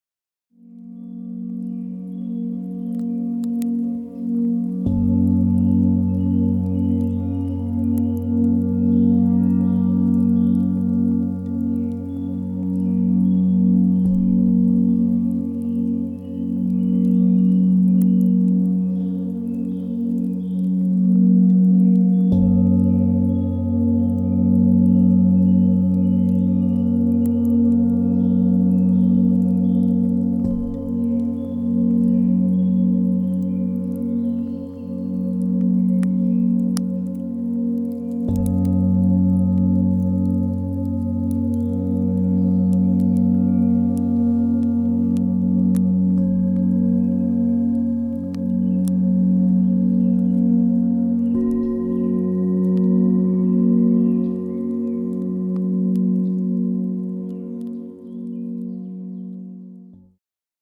Diese CD besitzt sehr tiefe und sehr hohe Töne.